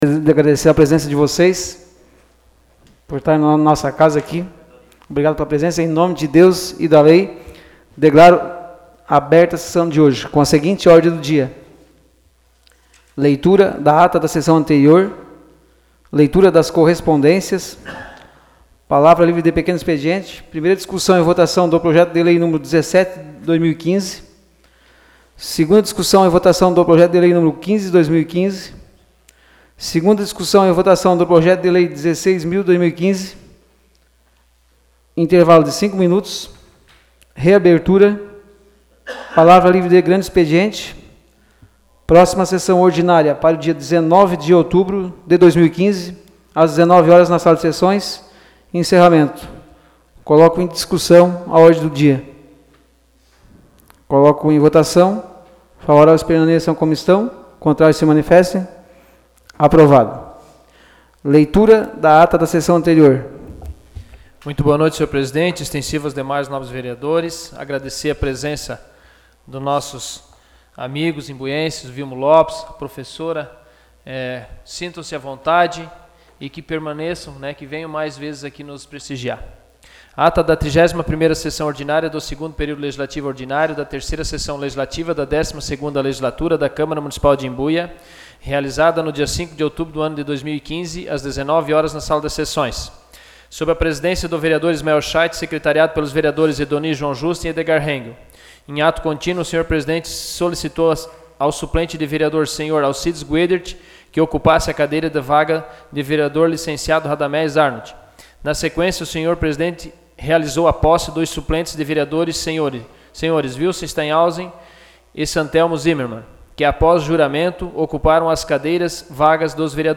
Áudio da Sessão Ordinária de 09 de outubro de 2015.